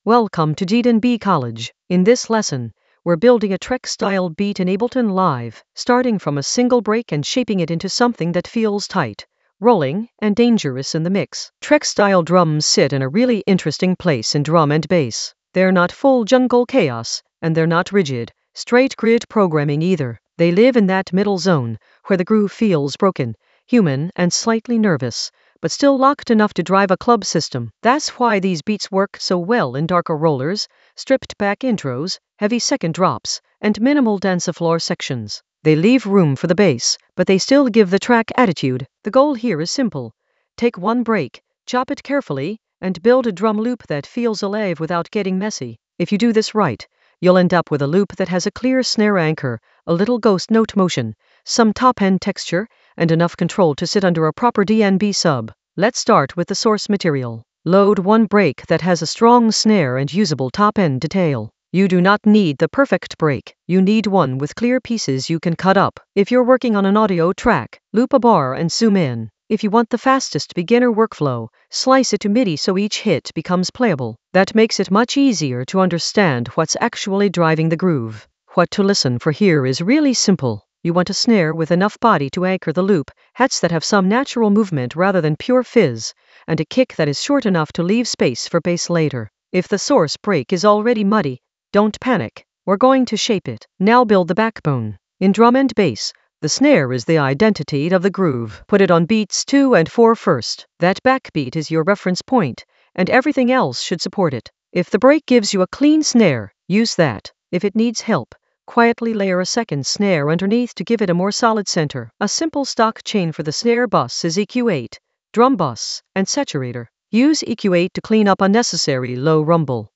An AI-generated beginner Ableton lesson focused on Trex style beats in the Drums area of drum and bass production.
Narrated lesson audio
The voice track includes the tutorial plus extra teacher commentary.